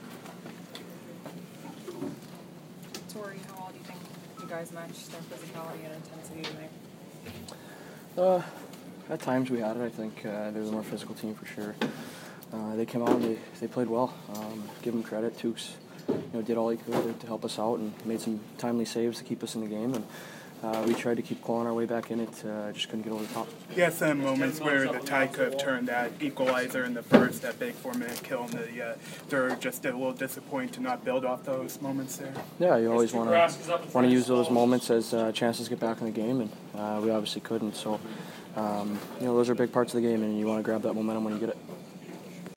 Torey Krug post-game 4/30